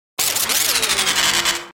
جلوه های صوتی
دانلود صدای ربات 20 از ساعد نیوز با لینک مستقیم و کیفیت بالا